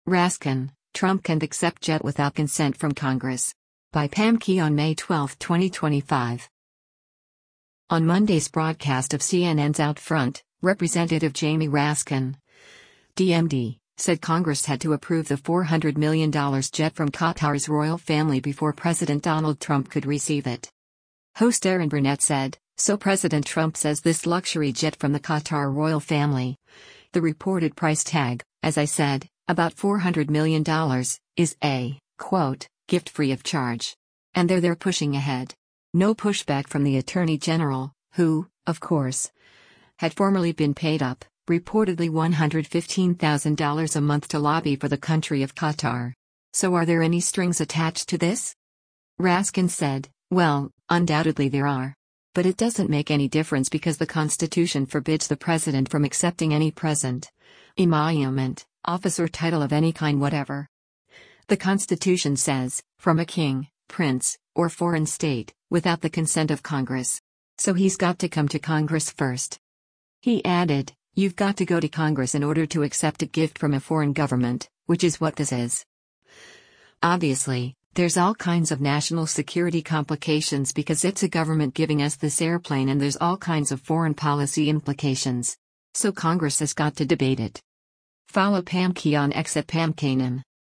On Monday’s broadcast of CNN’s “OutFront,” Rep. Jamie Raskin (D-MD) said Congress had to approve the $400 million jet from Qatar’s royal family before President Donald Trump could receive it.